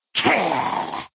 11 KB Category:Fallout: New Vegas creature sounds 1
FNV_GenericFeralGhoulAttack_Kill.ogg